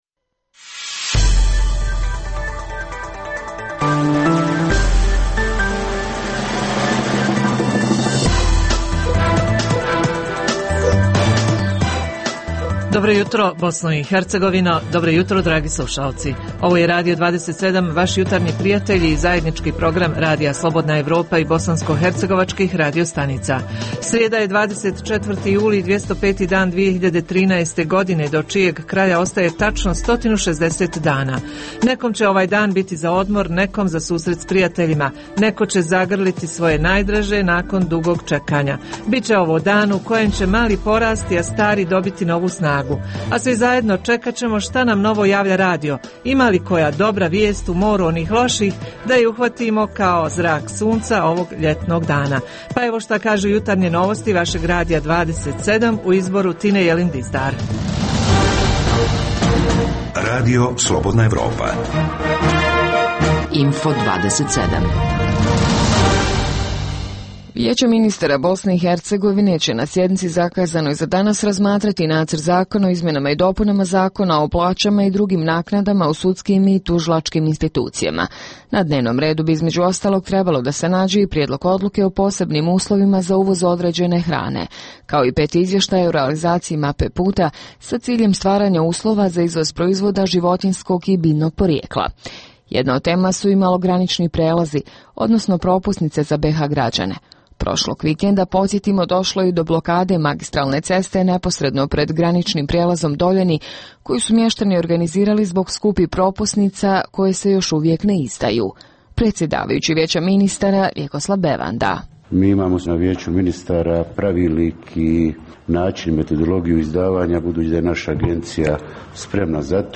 Uz tri pregleda vijesti, naši slušaoci mogu uživati u ugodnoj muzici.